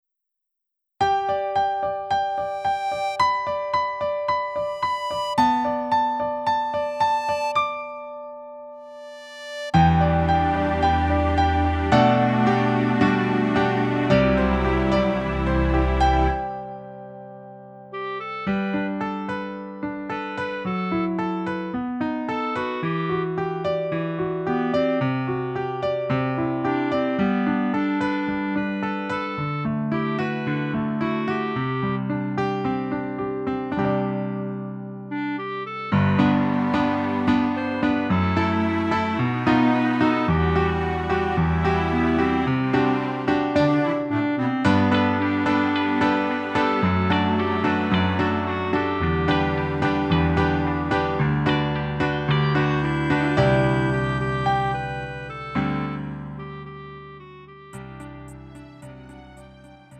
음정 -1키 4:09
장르 가요 구분 Lite MR